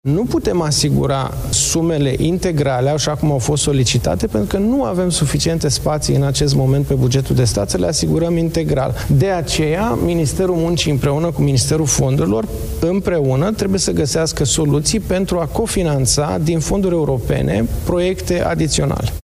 Ministrul Finanțelor, Alexandru Nazare: „Nu putem asigura sumele integrale așa cum au fost solicitate, pentru că nu avem suficiente spații în acest moment pe bugetul de stat”